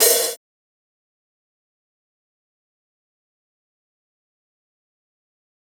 Metro OH5.wav